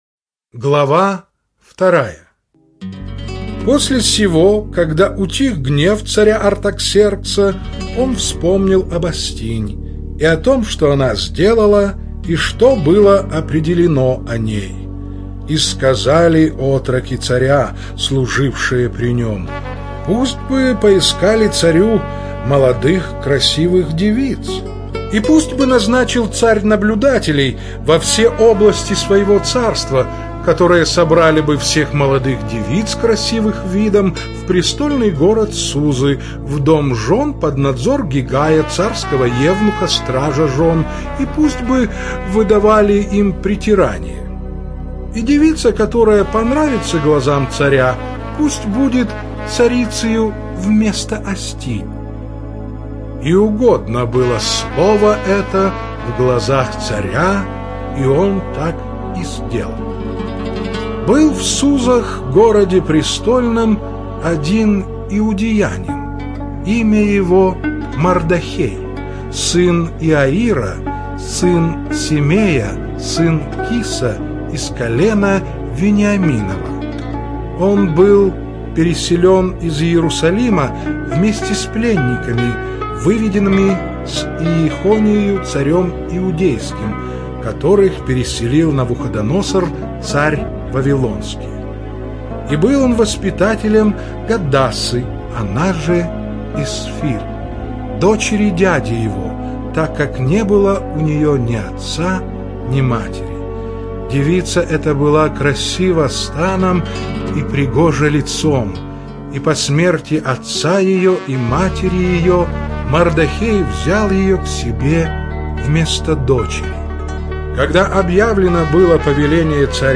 Аудио Библия - Слушать Ветхий завет онлайн mp3
Чтение сопровождается оригинальной музыкой и стерео-эффектами